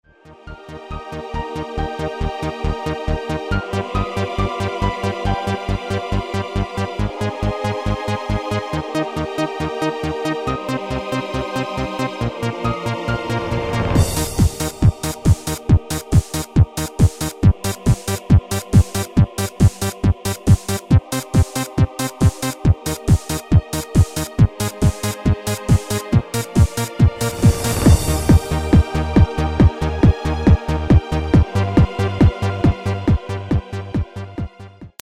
Genre: Dance / Techno / HipHop / Jump
Toonsoort: D#
Demo's zijn eigen opnames van onze digitale arrangementen.